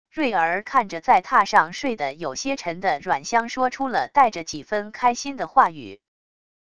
睿儿看着在榻上睡得有些沉的阮湘说出了带着几分开心的话语wav音频